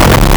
Player_Glitch [18].wav